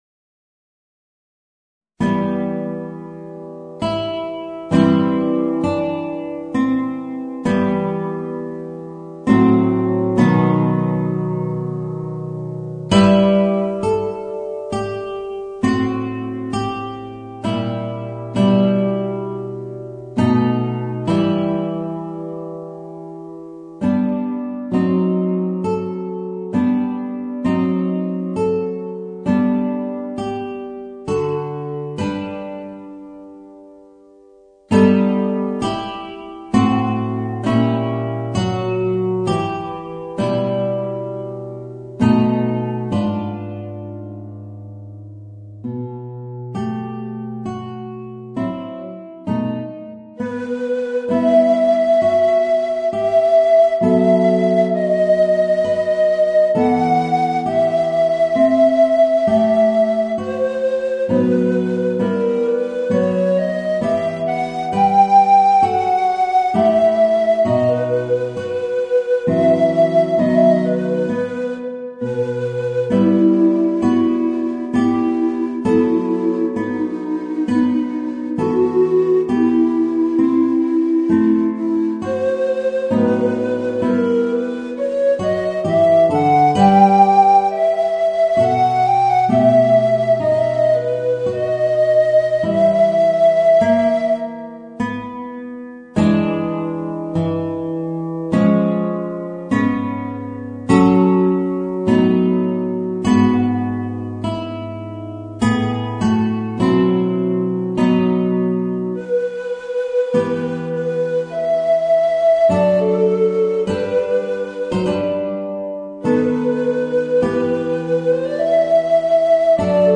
Voicing: Tenor Recorder and Guitar